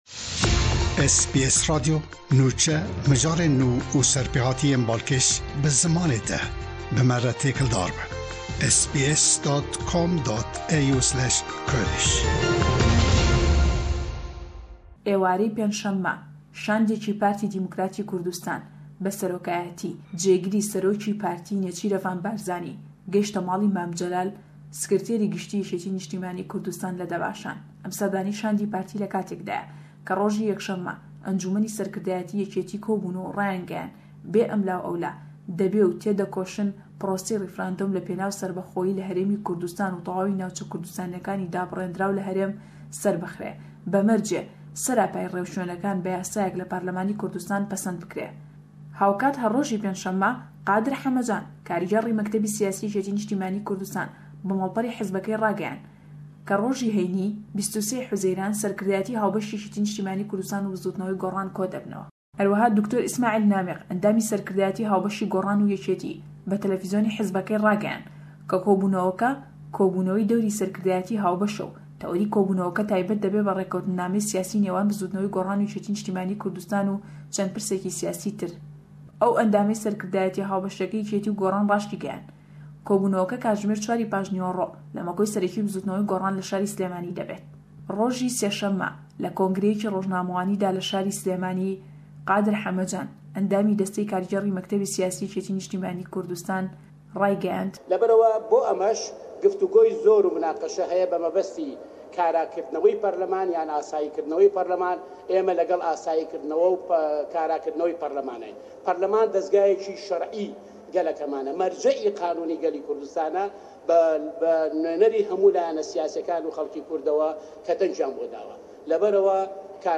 ji Slêmaniyê nûcheyên herî dawîn ji Herêma Kurdistanê radighîne, bi taybetî mijara bi rêfrendomê de girêdayî.